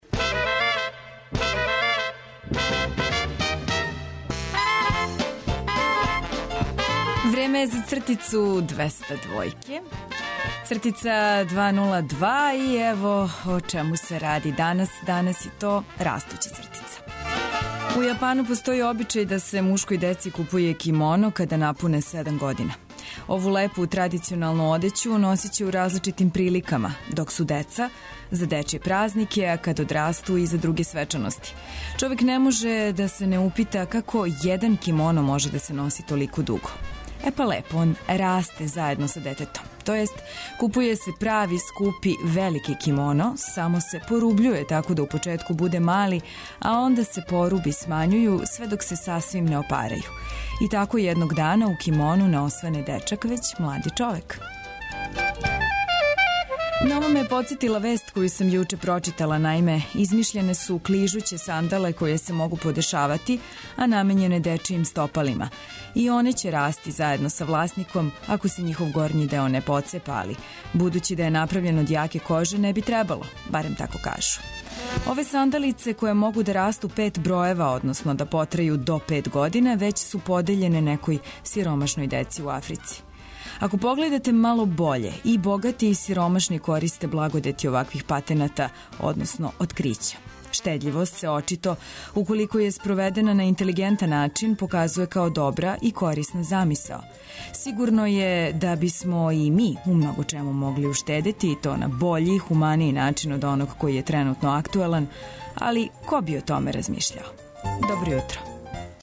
Нама не треба спонзор да бисмо емитовали вести, временску прогнозу или стање на путевима. Новости које је корисно чути рано ујутру, добру музику и различите прилоге, слушајте од 6-9 у Устанку.